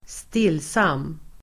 Ladda ner uttalet
stillsam adjektiv (mest om person), quiet , tranquil Uttal: [²st'il:sam:] Böjningar: stillsamt, stillsamma Synonymer: dämpad, fridfull, fridsam, lugn, lugna, lågmäld Definition: stilla, fridsam, lugn
stillsam.mp3